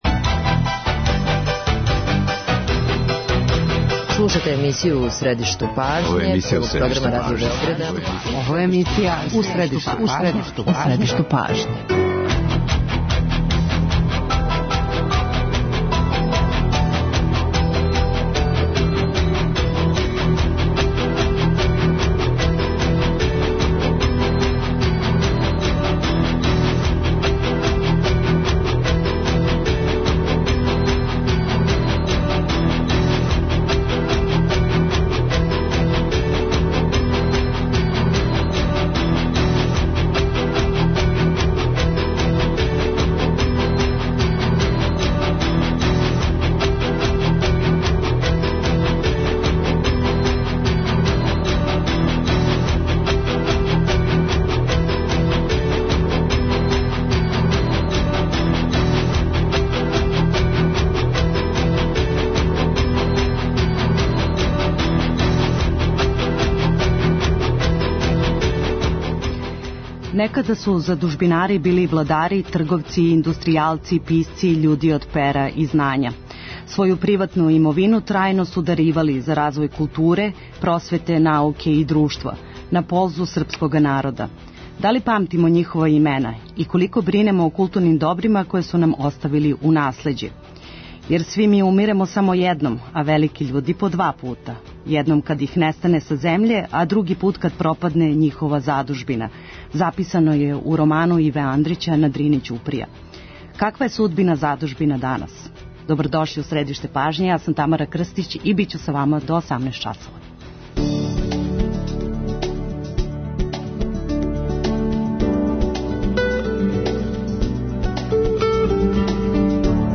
[ детаљније ] Све епизоде серијала Аудио подкаст Радио Београд 1 Београд на ногама Звук монголских степа у 21. веку Пшеница у добром стању Софија Соја Јовановић Диана Будисављевић